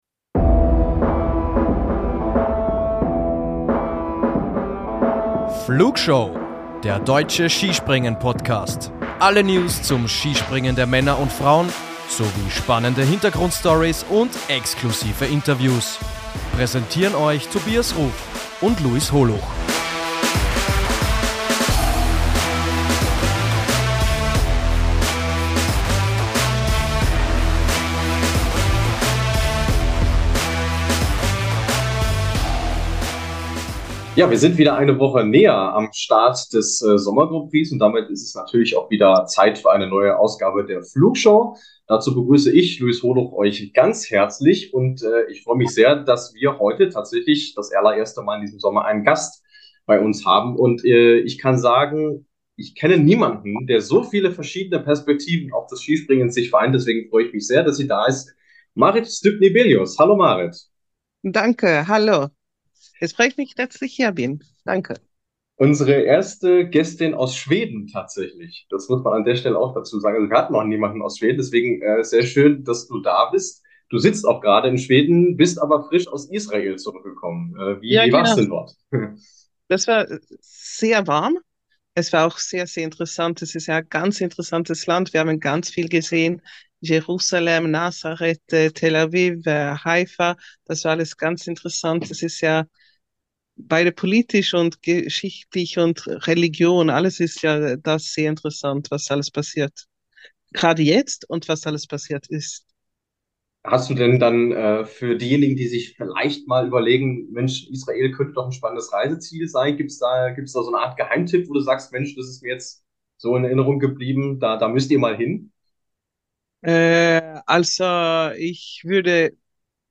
Noch dazu spricht sie Deutsch und ist daher die perfekte Gesprächspartnerin für unser erstes Sommer-Interview.